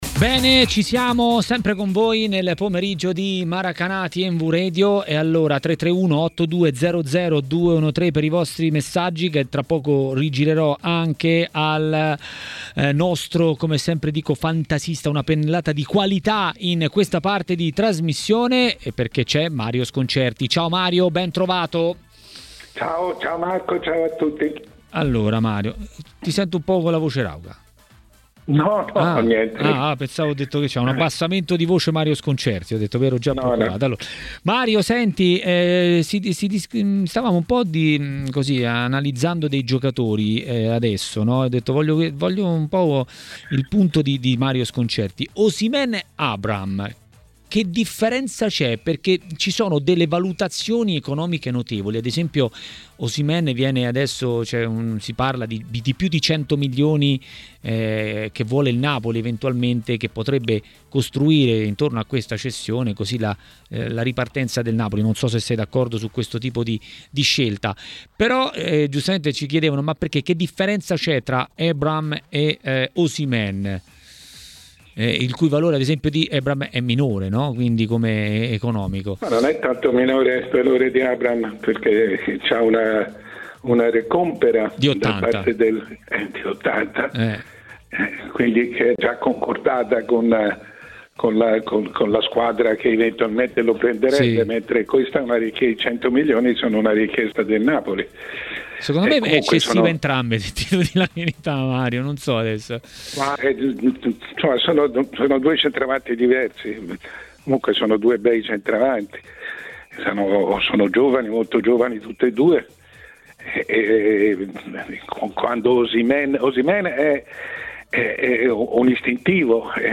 Il direttore Mario Sconcerti a Maracanà, trasmissione di TMW Radio, ha parlato dei grandi centravanti del campionato italiano
Ospite: Mario Sconcerti.